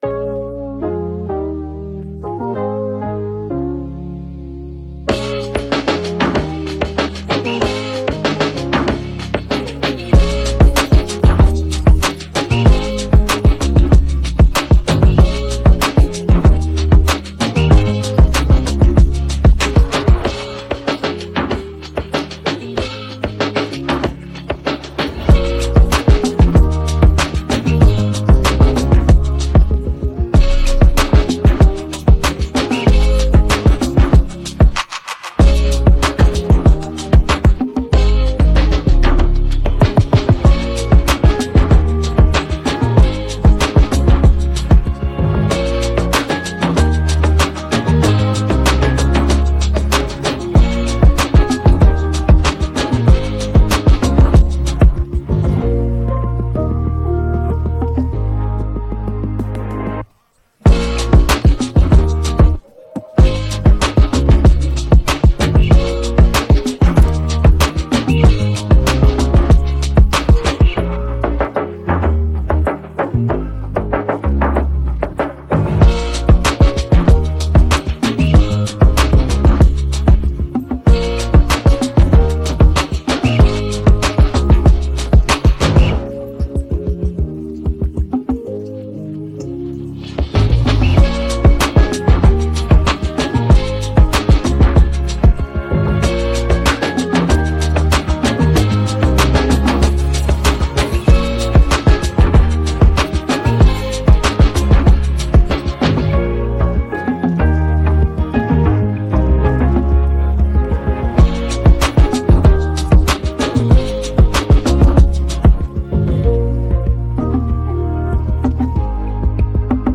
Known for blending afro-fusion and reggae